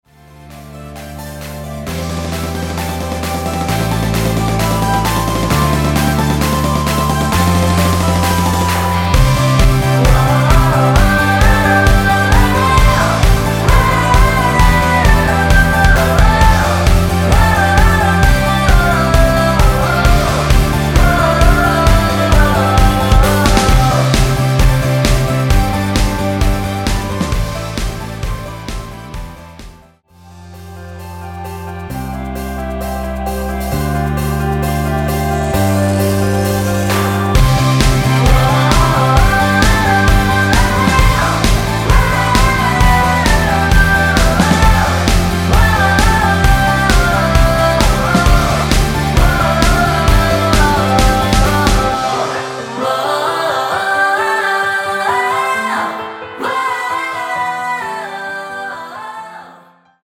원키에서(+3)올린 멜로디와 코러스 포함된 MR입니다.(미리듣기 확인)
앞부분30초, 뒷부분30초씩 편집해서 올려 드리고 있습니다.
중간에 음이 끈어지고 다시 나오는 이유는